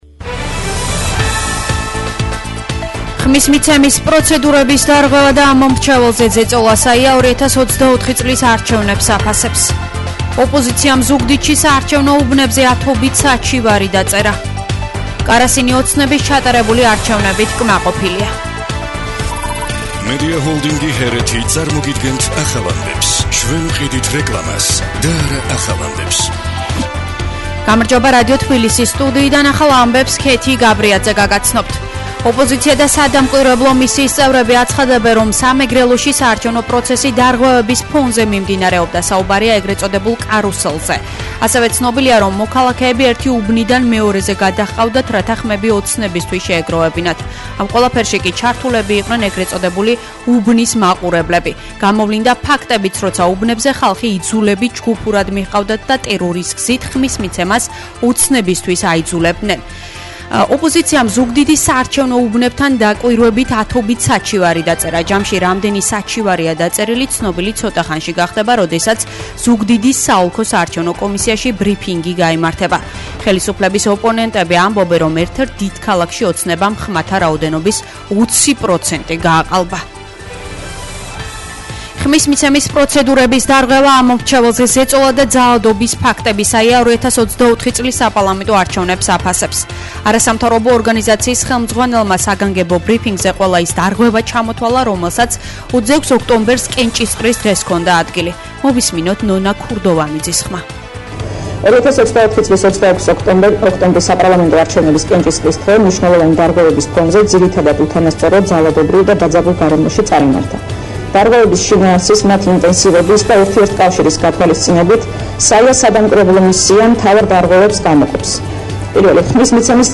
ახალი ამბები